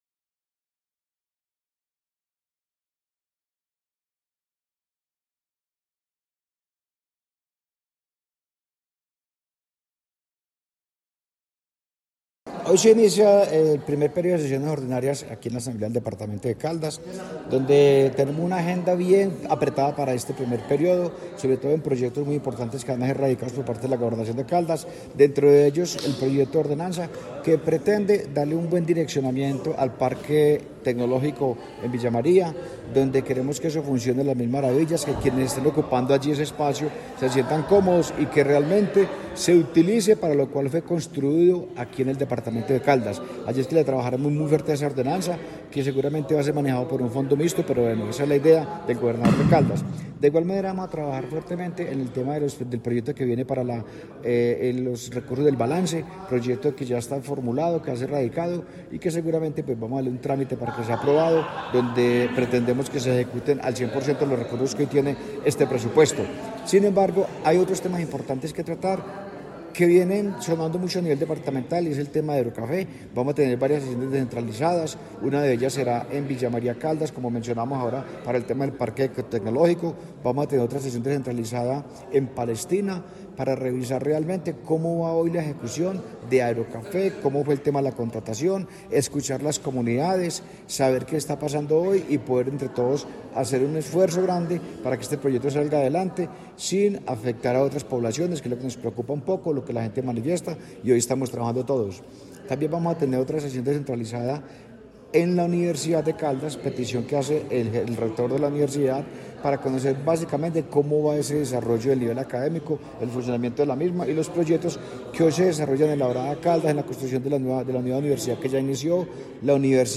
Jahir de Jesús Álvarez, presidente de la Asamblea de Caldas
Jahir-de-Jesus-Alvarez-presidente-Asamblea-de-Caldas1.mp3